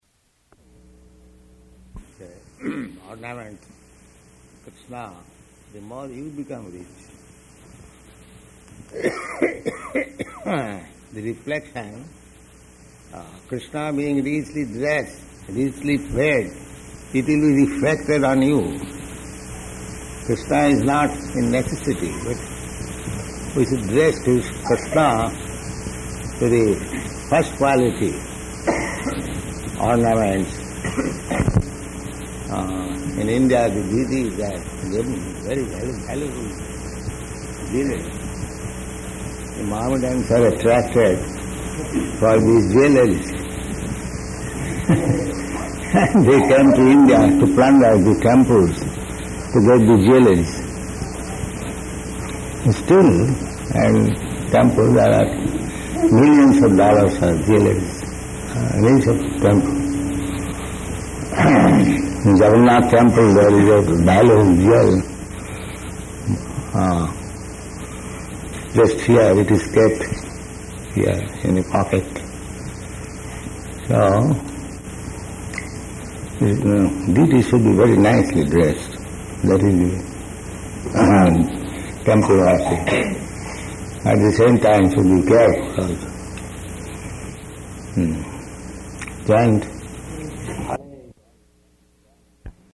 Talk
Location: Los Angeles